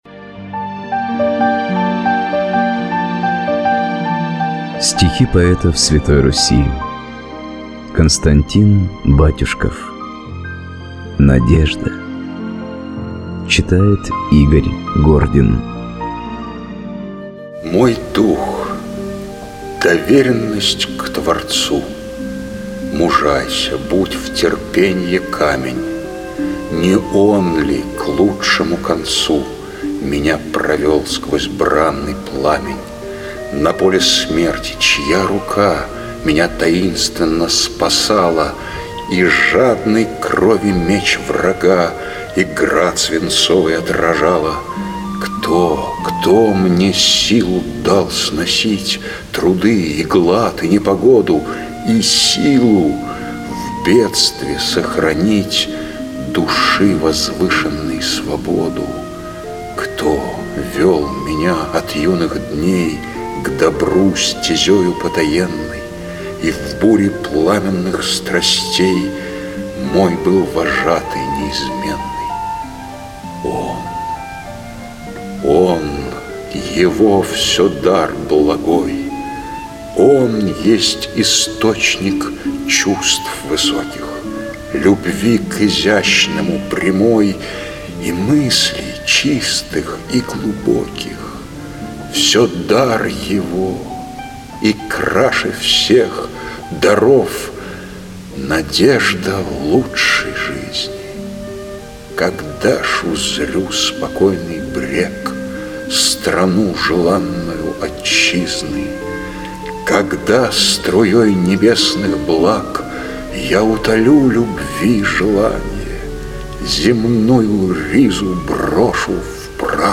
1. «Батюшков Константин – Надежда (чит. Игорь Гордин)» /